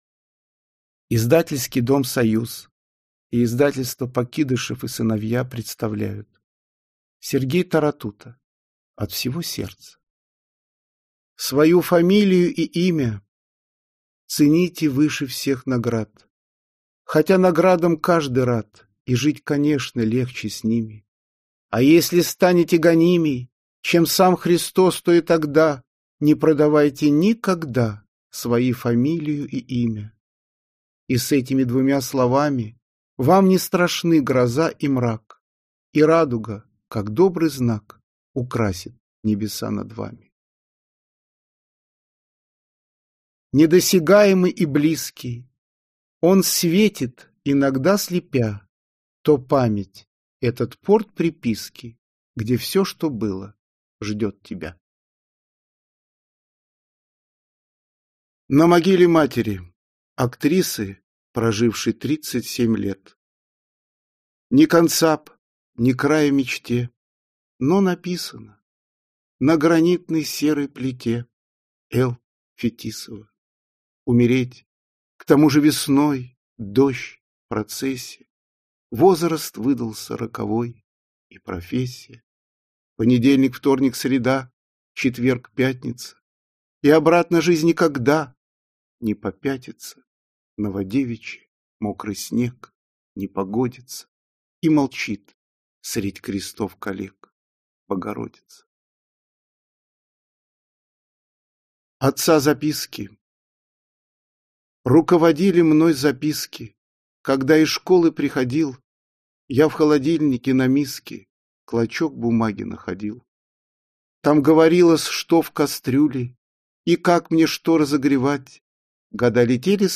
Аудиокнига От всего сердца | Библиотека аудиокниг